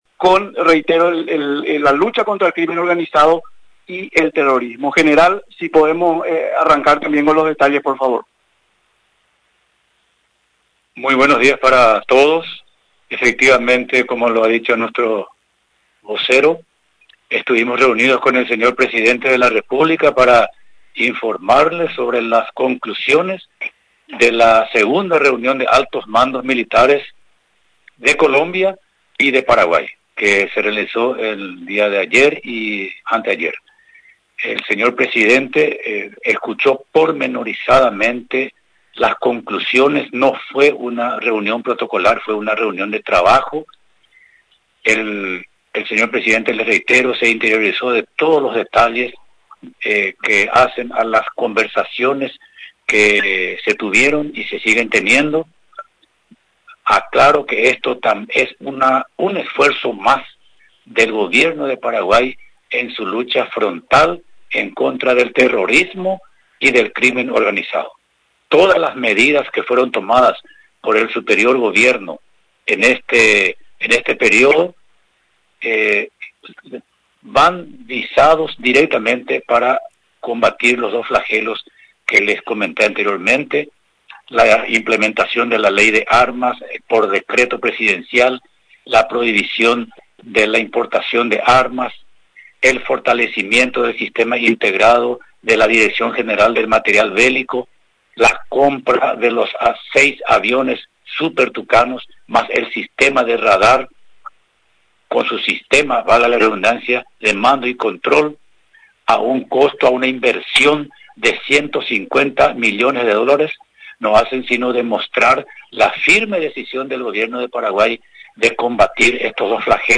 Paraguay y Colombia, anuncian ampliación de la cooperación en materia militar, anunciaron este jueves, en rueda de prensa realizada en la Residencia Presidencial de Mburuvichá Róga.